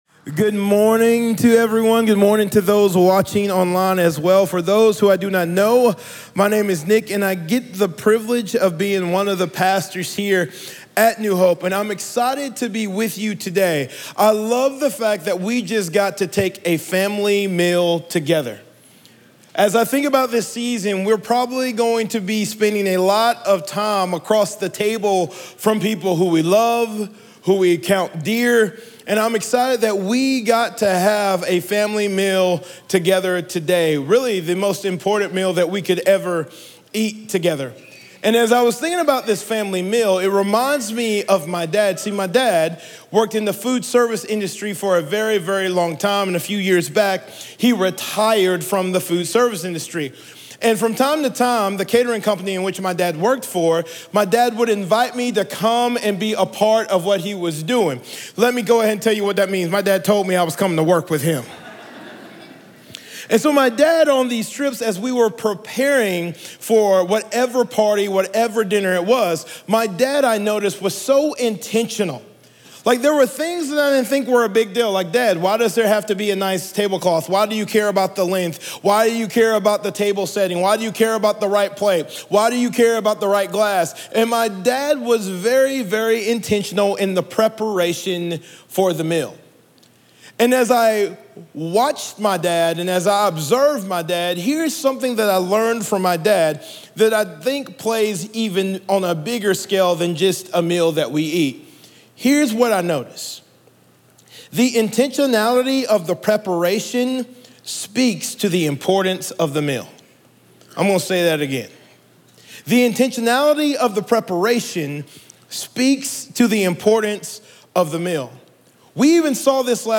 Podcasts sobre Sermon Series